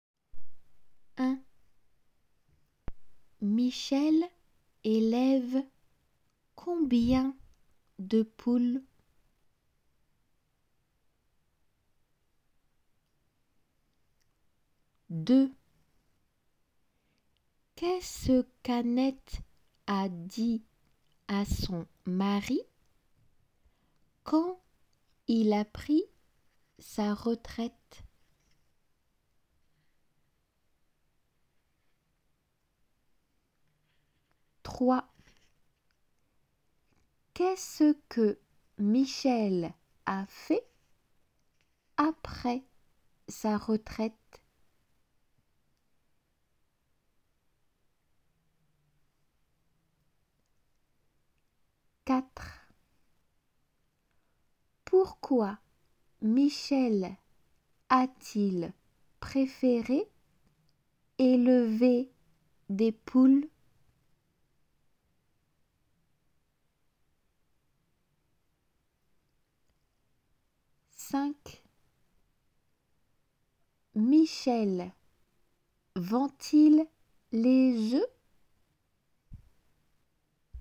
読まれる質問